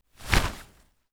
FABRIC_Flap_03_mono.wav